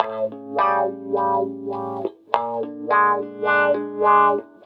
VOS GTR 1 -R.wav